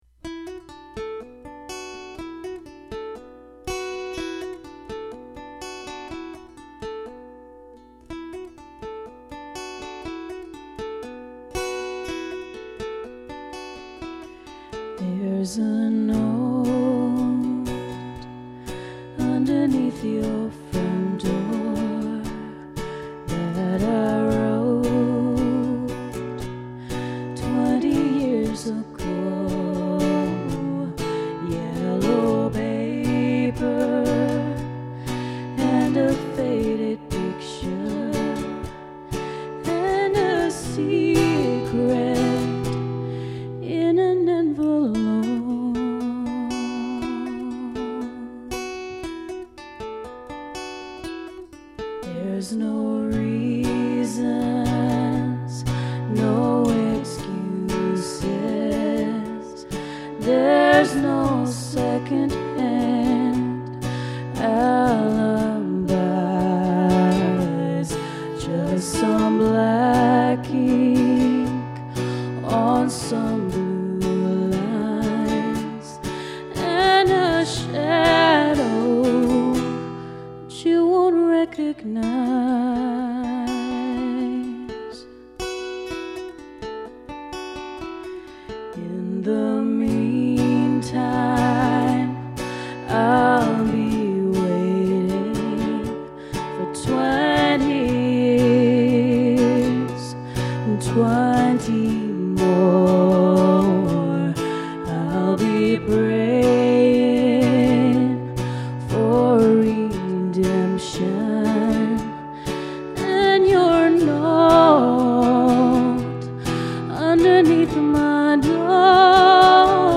Vocals
Guitar